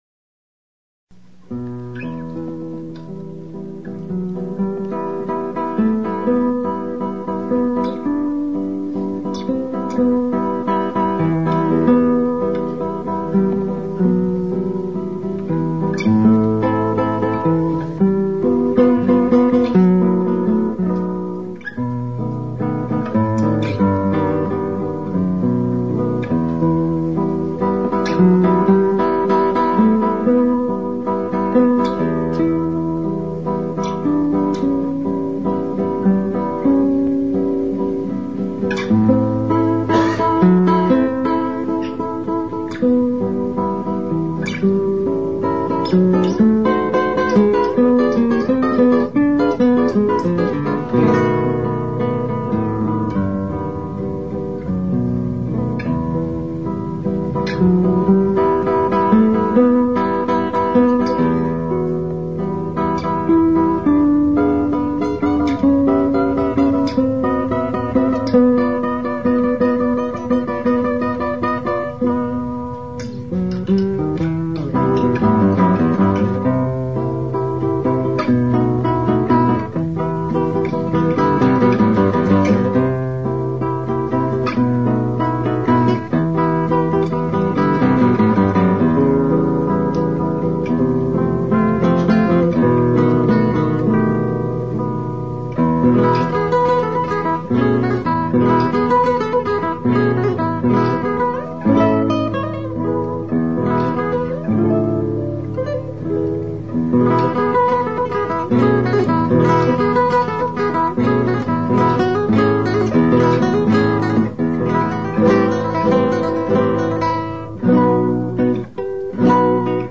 Kresge Little Theater MIT Cambridge, Massachusetts USA